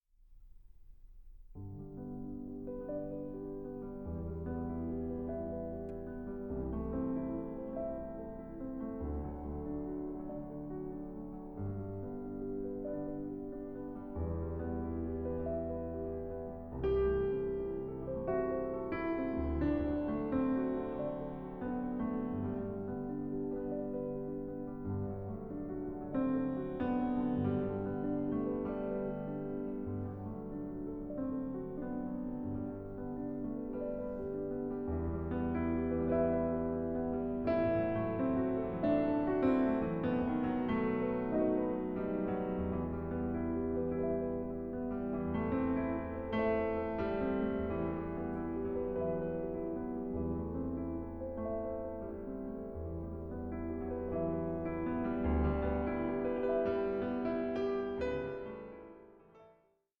Suite for Piano Duo